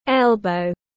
Elbow /ˈel.bəʊ/